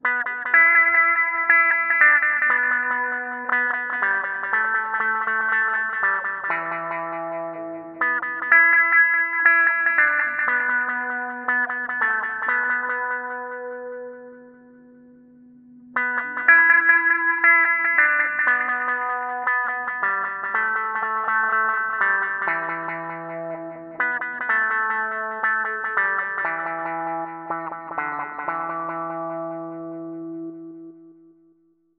玻璃红酒杯碰杯
描述：Studio Projects 麦克风，TL Audio 5051单声道处理器
标签： 干杯 红酒 玻璃 浪漫 高脚杯
声道立体声